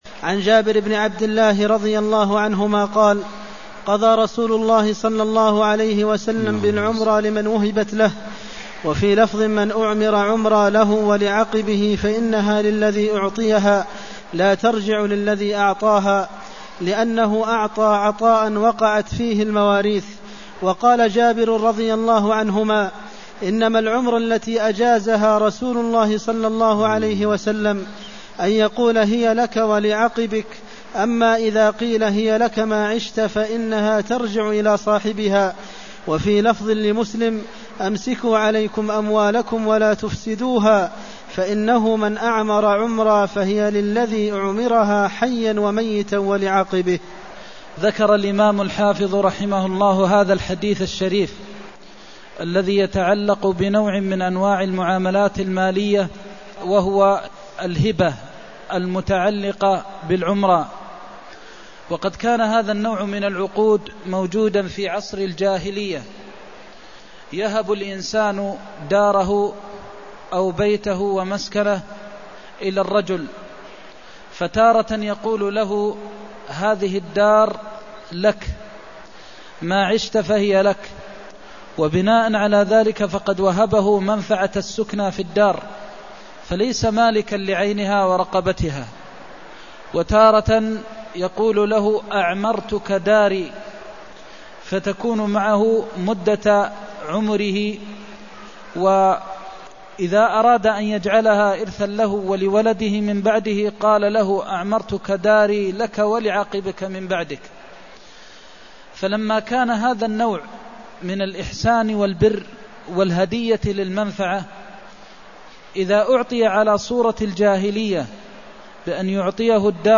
المكان: المسجد النبوي الشيخ: فضيلة الشيخ د. محمد بن محمد المختار فضيلة الشيخ د. محمد بن محمد المختار قضى رسول الله بالعمرى لمن وهبت له (274)